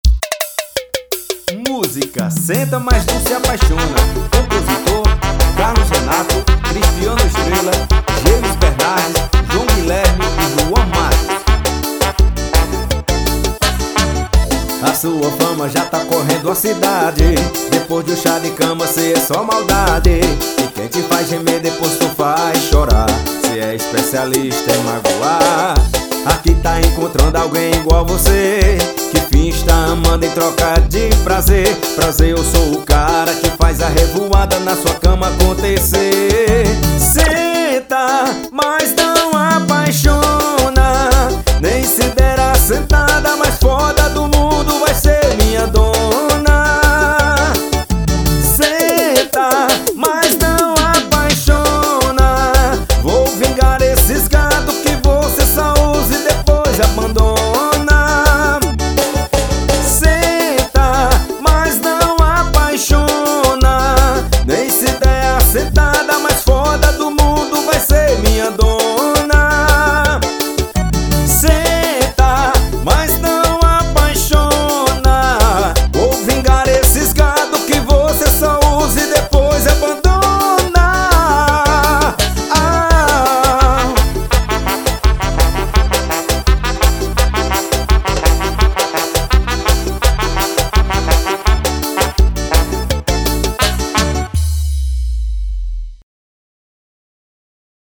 Sertanejo Modao